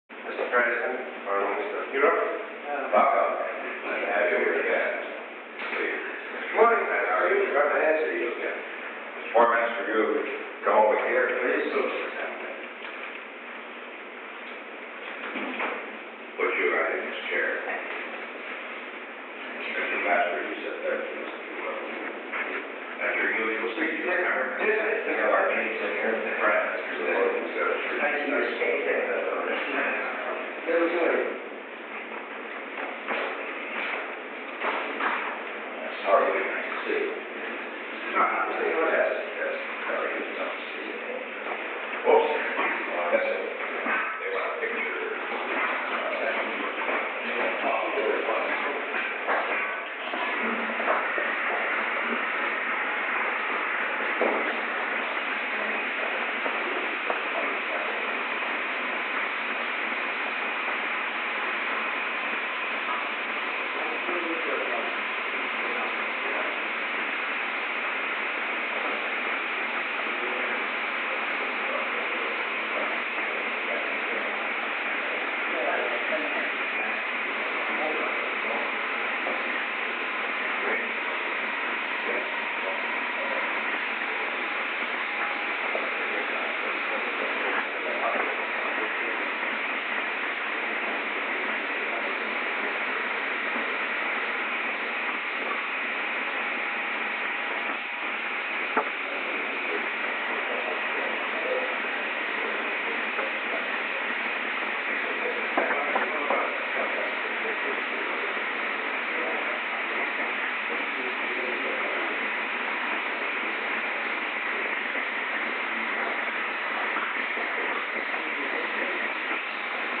Recording Device: Oval Office
The Oval Office taping system captured this recording, which is known as Conversation 803-002 of the White House Tapes.